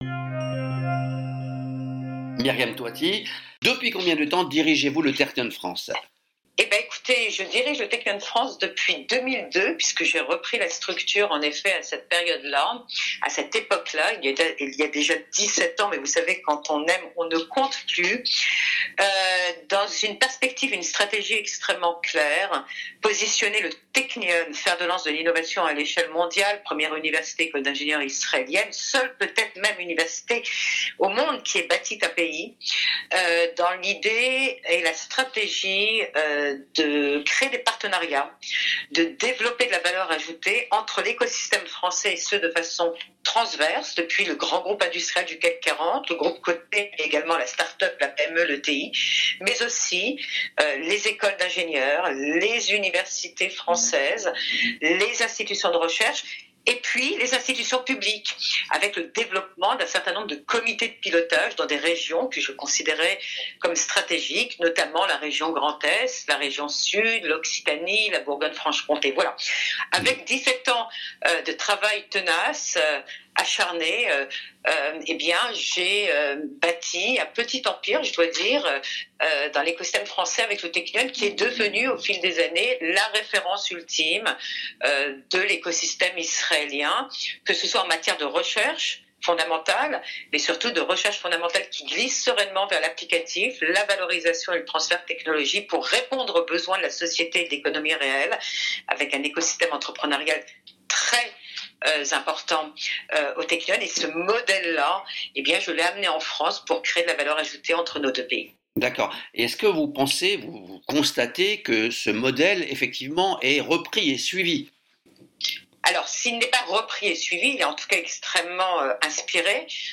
entretien audio exclusif.